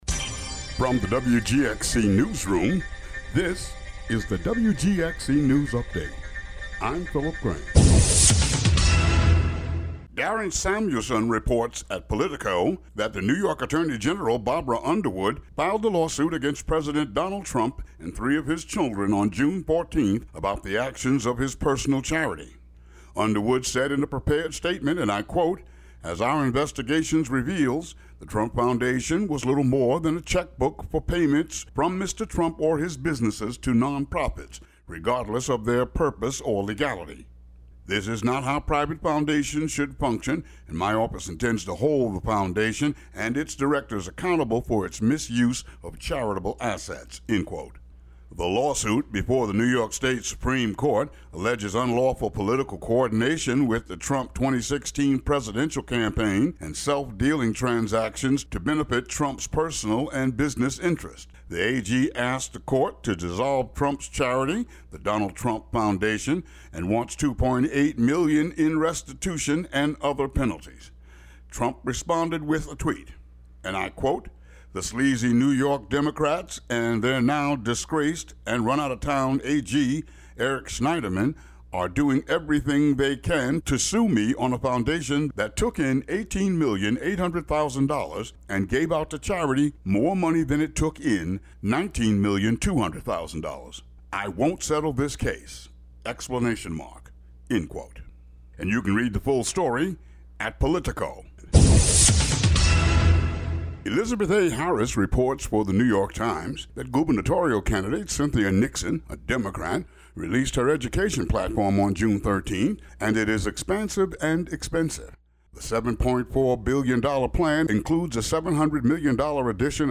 "All Together Now!" is a daily news show brought t...
WGXC Local News Update Audio Link